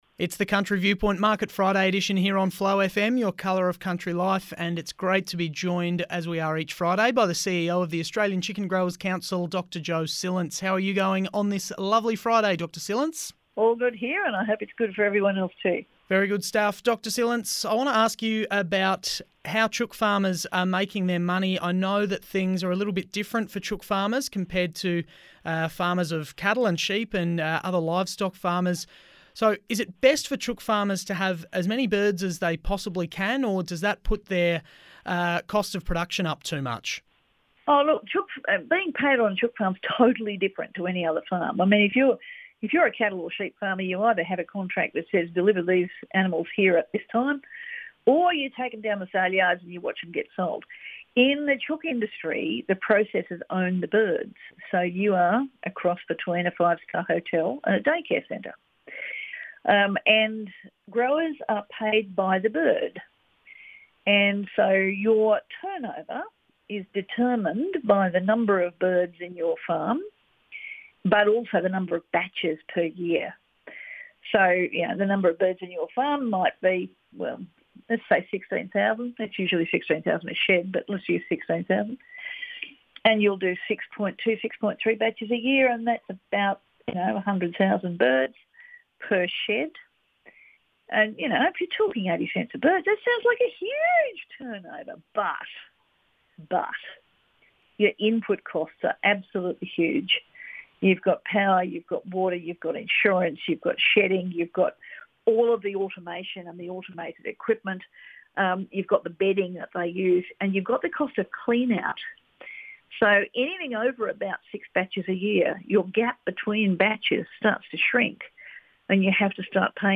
Radio Interviews on Flow FM, South Australia